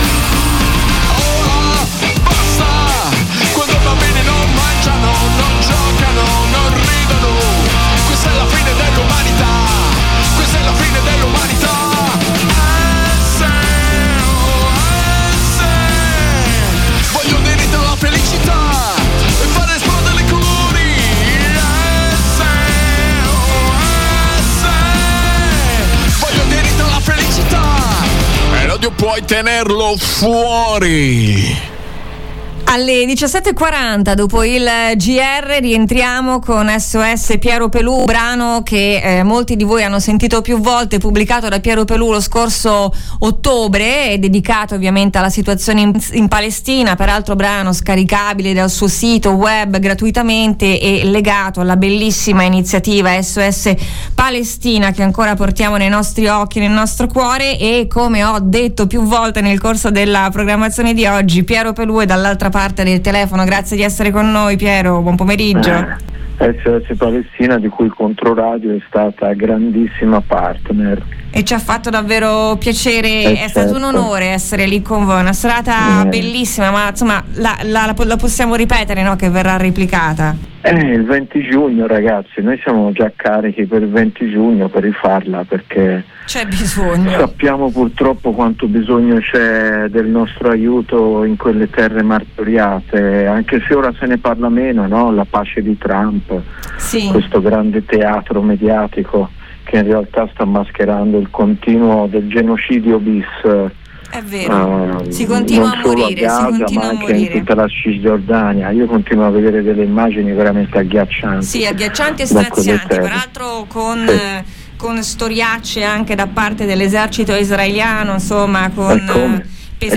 Piero Pelù, il rumore dell’anima. Ascolta l’intervista!